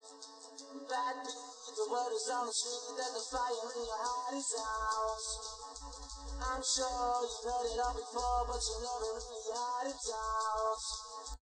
Example Audio: Best SIR from Sparsity introduced in W